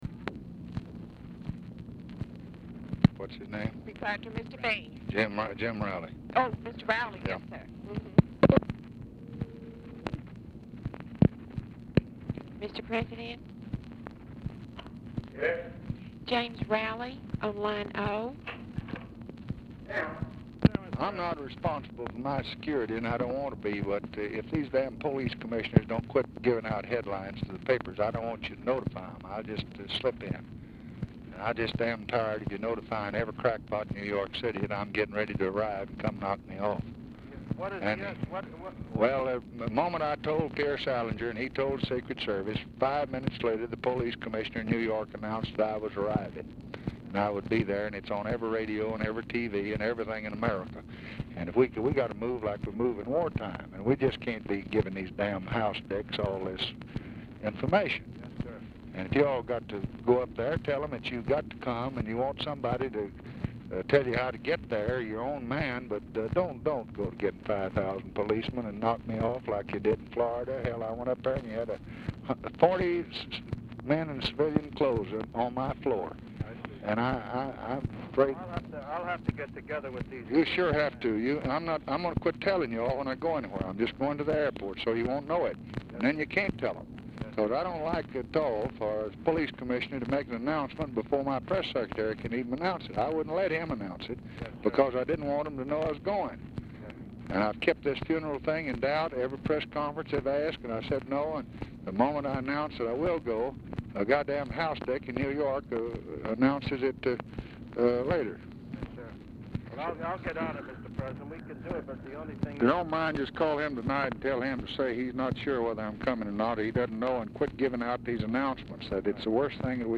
Telephone conversation # 2349, sound recording, LBJ and JAMES ROWLEY, 3/4/1964, 7:56PM | Discover LBJ
Format Dictation belt
Location Of Speaker 1 Oval Office or unknown location